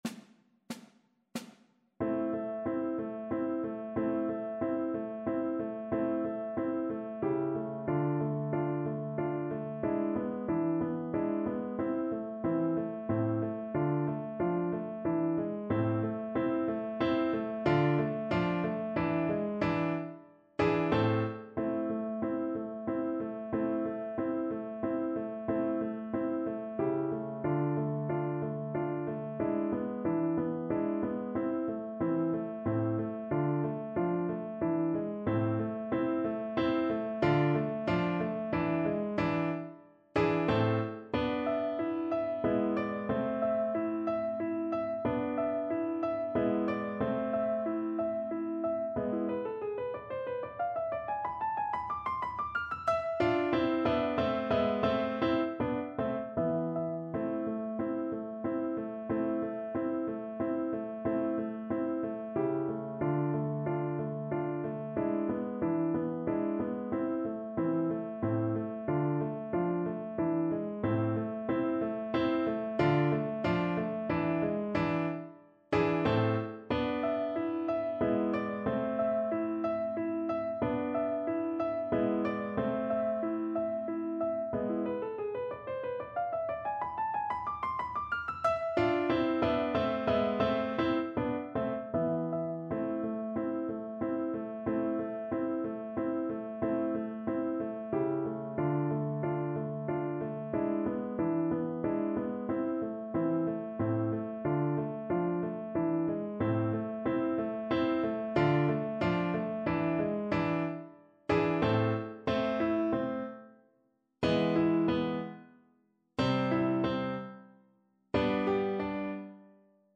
Ogiński: Polonez „Pożegnanie ojczyzny” (na skrzypce i fortepian)
Symulacja akompaniamentu